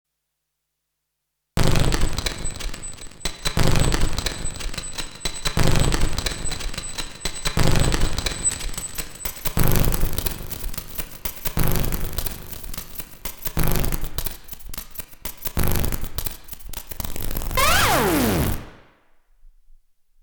• Digitone operators offsets can go to -1
⇒ what happens when you I close to 0? How does the sound react to different octaves? What about getting an envelope on the pitch? How different harmonics react to such configuration?